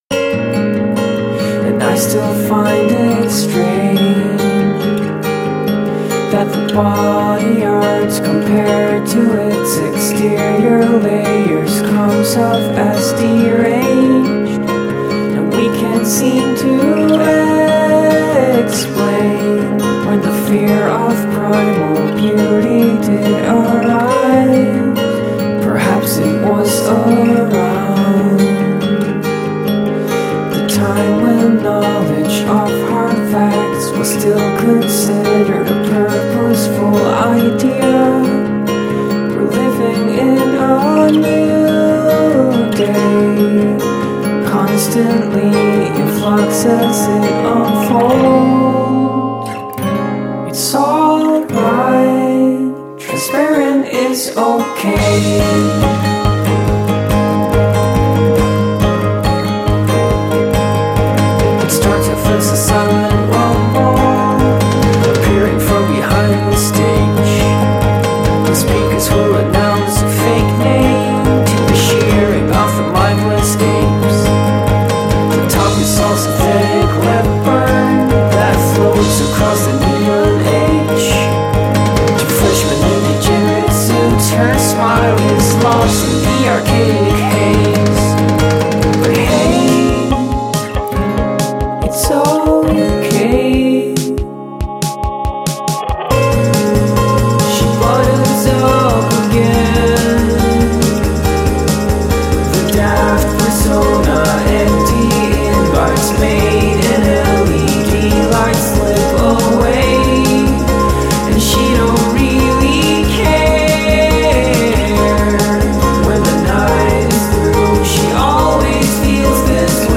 alternate a un freddo indie rock venato di electro pop.